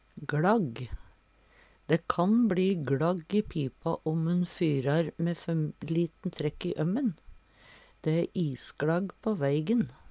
gLagg - Numedalsmål (en-US)